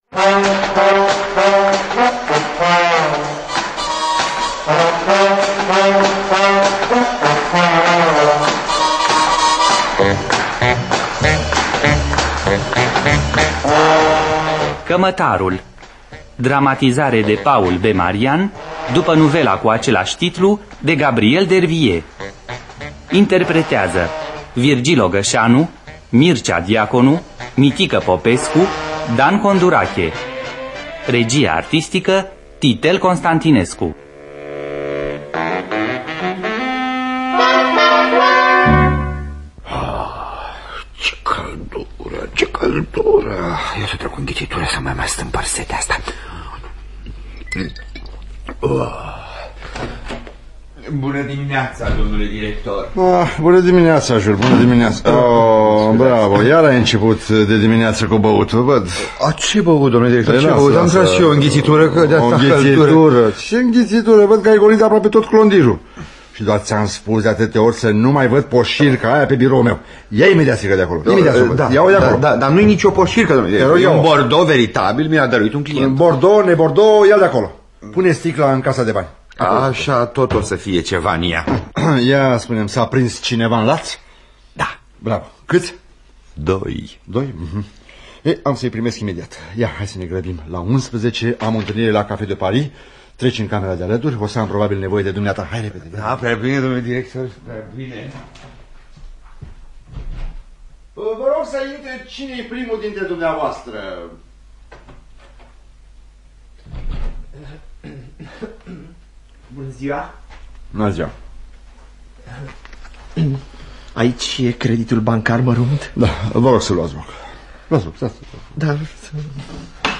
Dramatizarea de Paul B. Marian.
În distribuție: Virgil Ogășanu, Dan Condurache, Mircea Diaconu, Mitică Popescu.